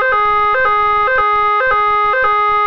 Sirena electrónica de gran potencia direccional
3 sonidos seleccionables: Bitonal Francés: AFNOR (554Hz/0,1s., con 440Hz/0,4s.)
BITONAL
BITONAL.wav